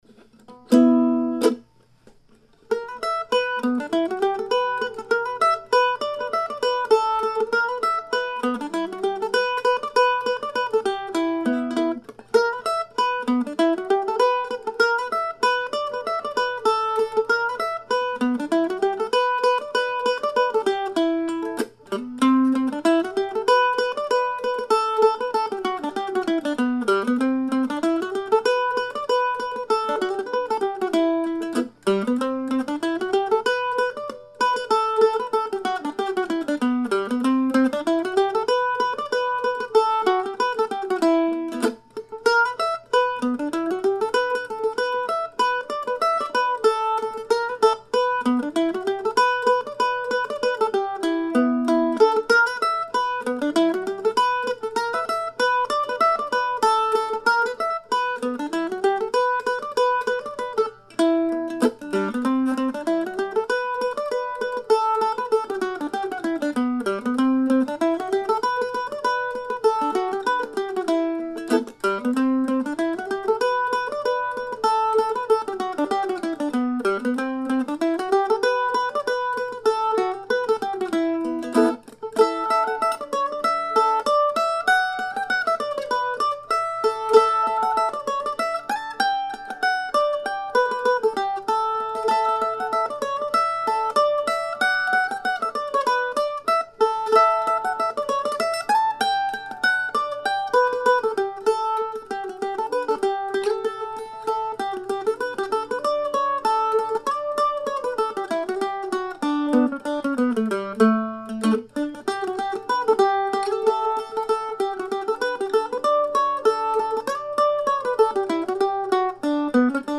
Two recently composed dance tunes with a breakfast theme, played a little below dance tempo, with a few more mistakes than usual.